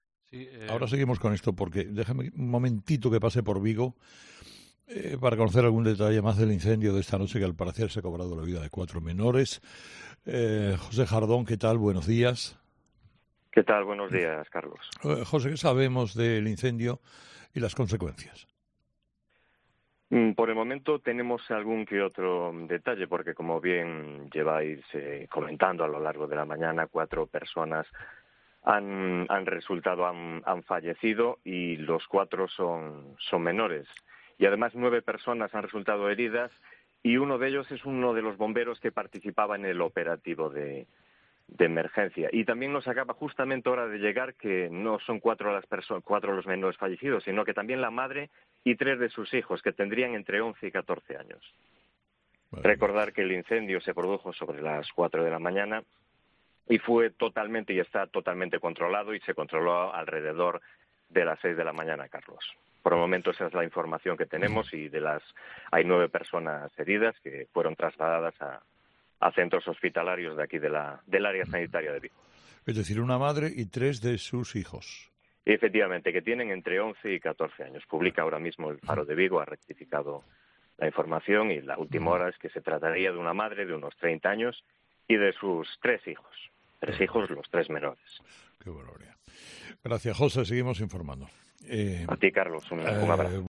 informa desde COPE Vigo del incendio con 4 fallecidos en Vigo